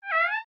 tbd-station-14/Resources/Audio/Animals/wawa_question.ogg at d1661c1bf7f75c2a0759c08ed6b901b7b6f3388c
wawa_question.ogg